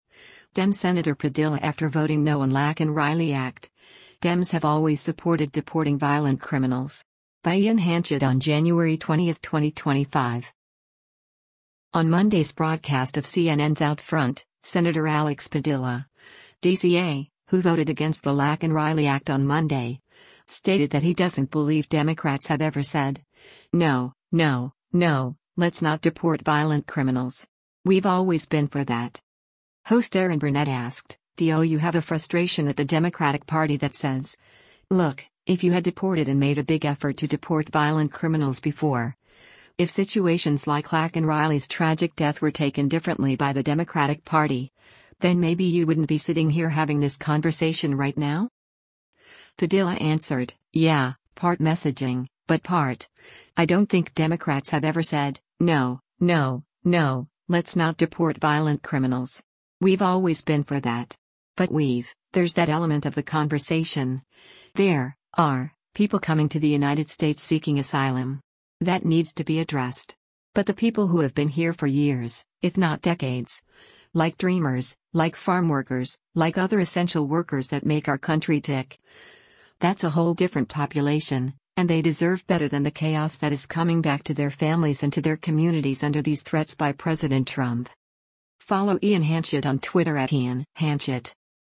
On Monday’s broadcast of CNN’s “OutFront,” Sen. Alex Padilla (D-CA), who voted against the Laken Riley Act on Monday, stated that he doesn’t believe “Democrats have ever said, no, no, no, let’s not deport violent criminals. We’ve always been for that.”